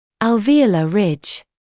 [ˌæl’viːələ rɪʤ][ˌэл’виːэлэ rɪʤ]десневой гребень